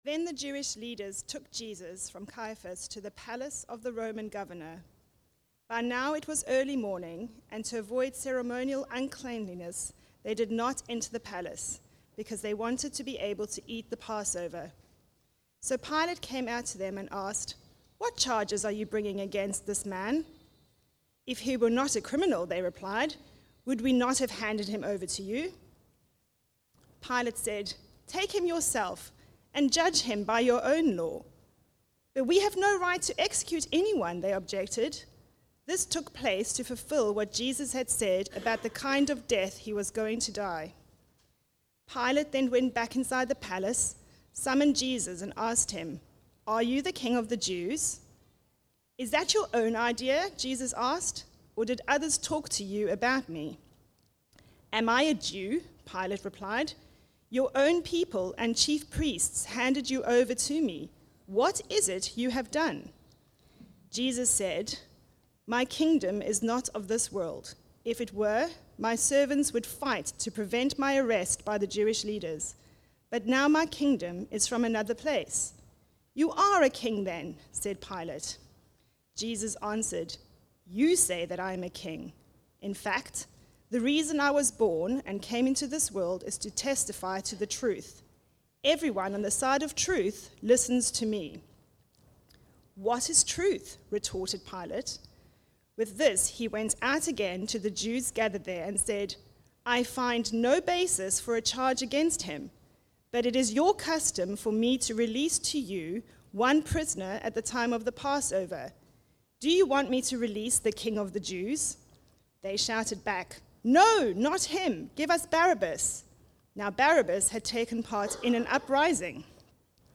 The Trial (John 18:28-19:16) from the series Life From Death. Recorded at Woodstock Road Baptist Church on 01 March 2026.